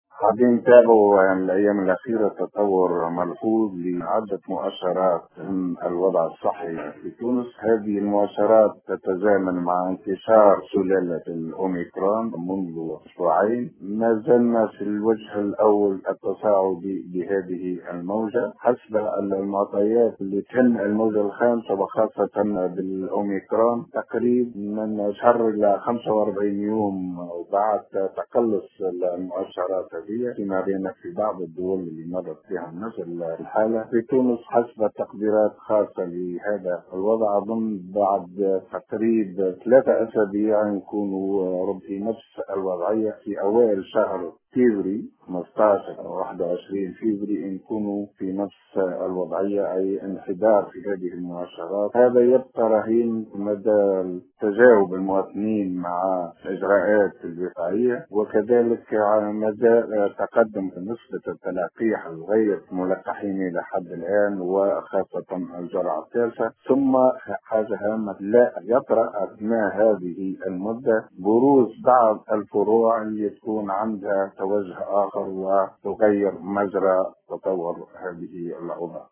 Dans une déclaration accordée aujourd’hui à Tunisie Numérique